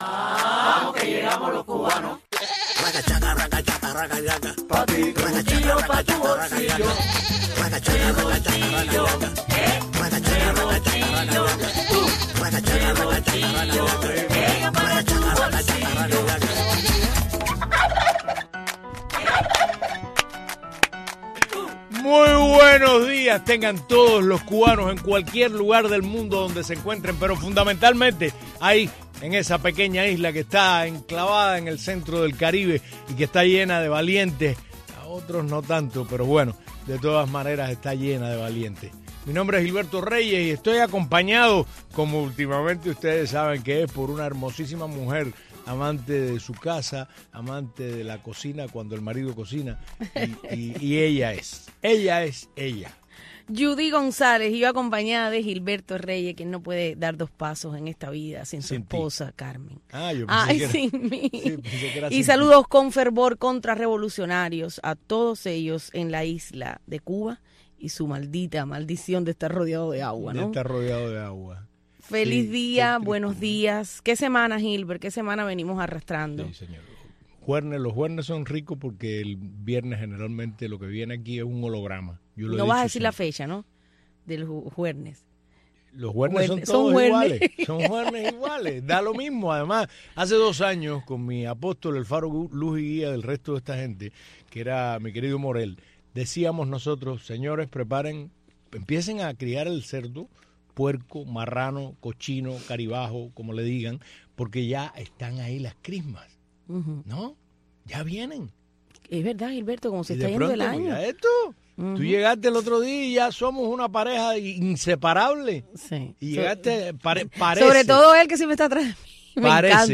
“El Revoltillo” es un entretenido programa matutino en el cual los conductores